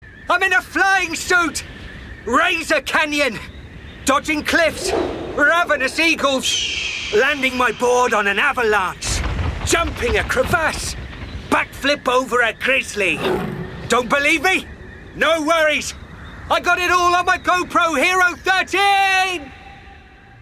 • Male
Showing: Commerical Clips
Enthusiastic, Exciting, Dramatic